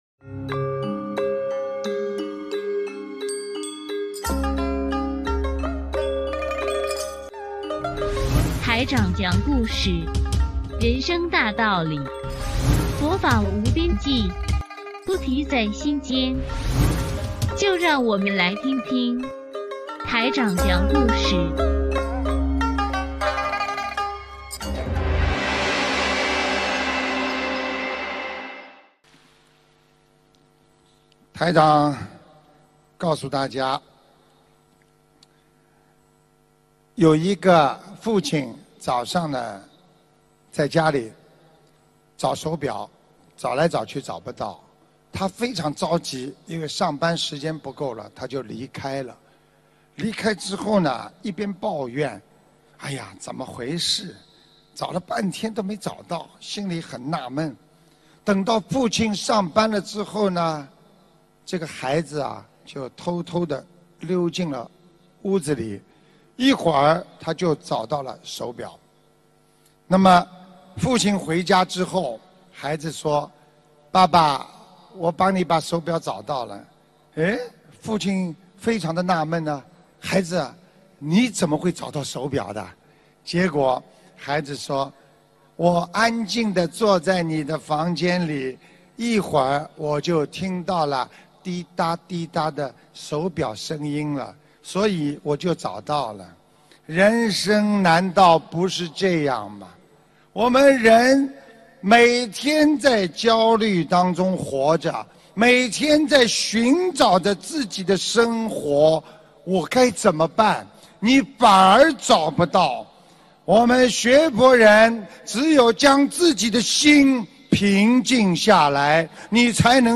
音频：找手表·师父讲小故事大道理